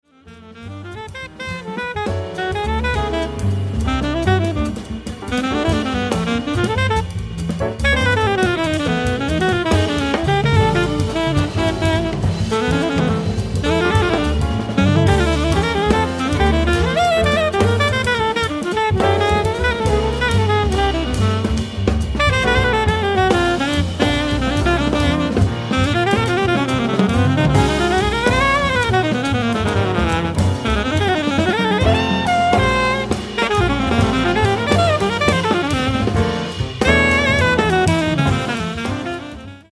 Recorded at Red Gables Studio 8th, 9th May 2008